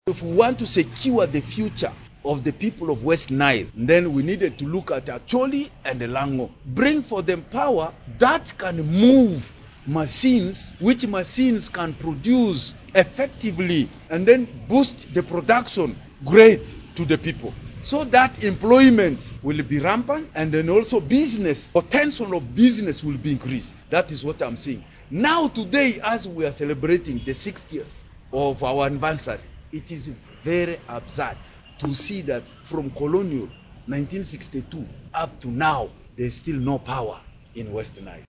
Alhaji Issa Kato, the former mayor of Arua city Speaking